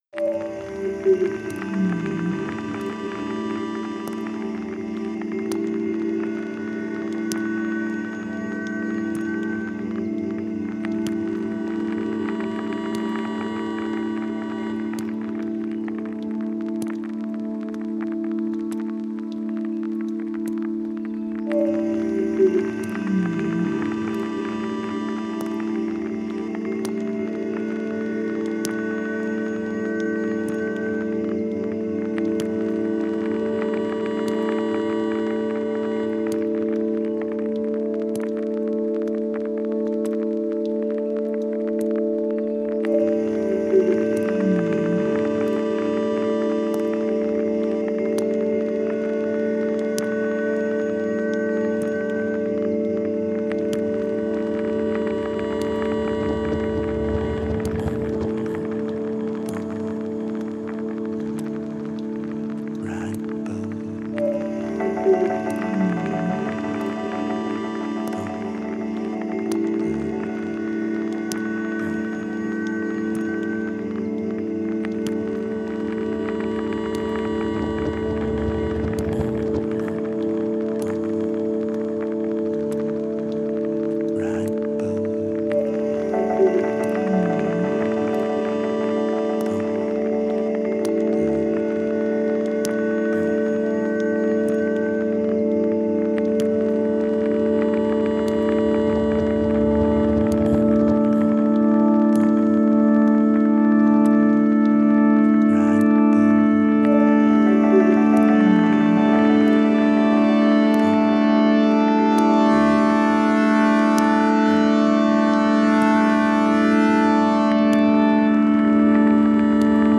Genre: Ambient, New Age, Meditative.